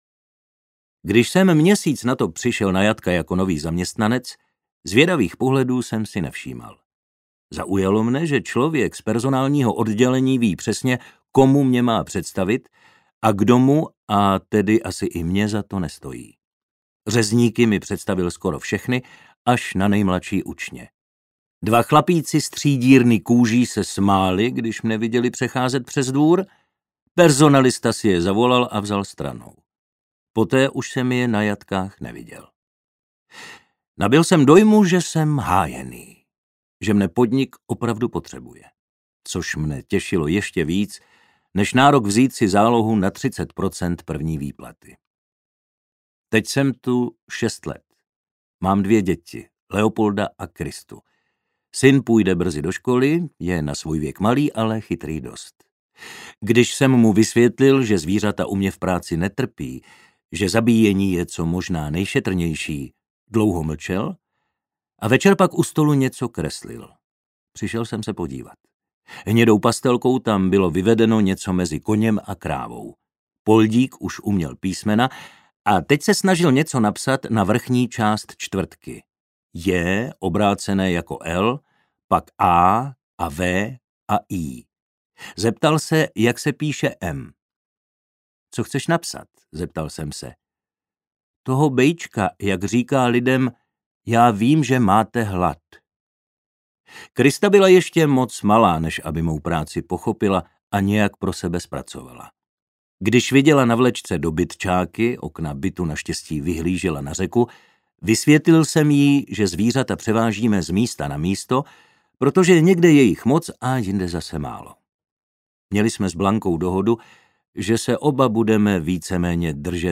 Továrna na maso audiokniha
Ukázka z knihy